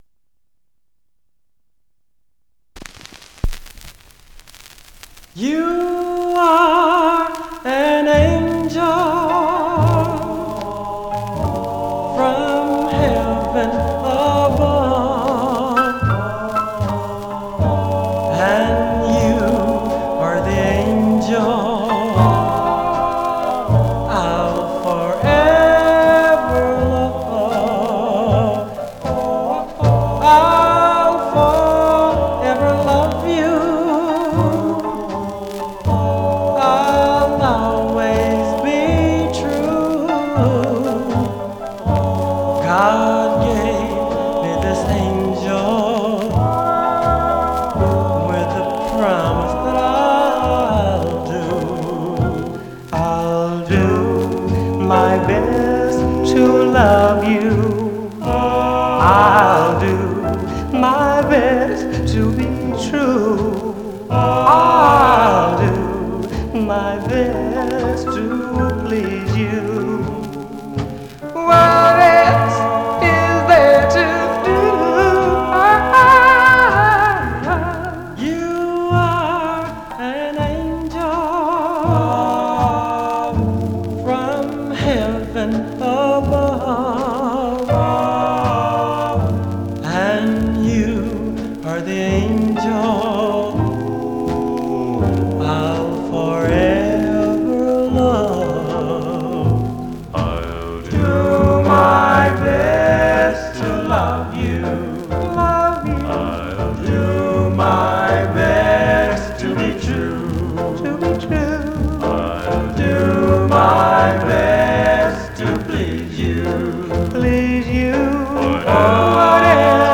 Surface noise/wear
Mono
Male Black Group Condition